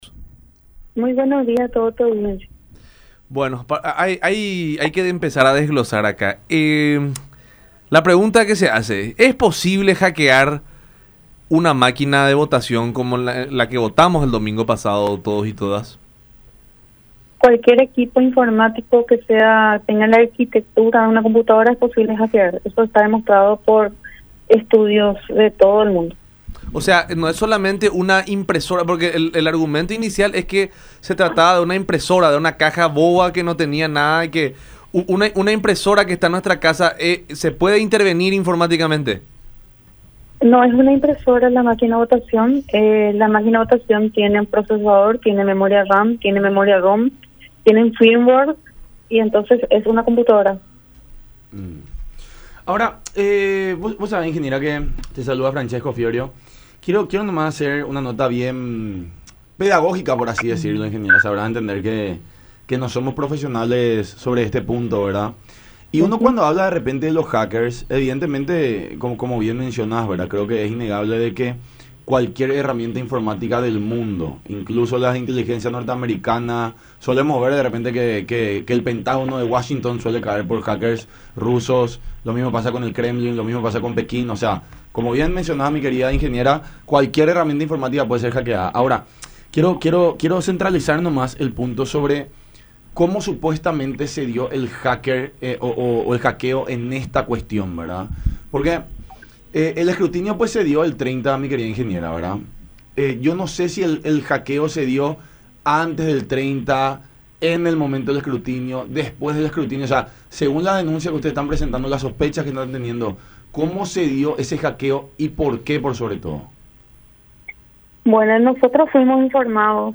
en conversación con La Unión Hace La Fuerza por Unión TV y radio La Unión.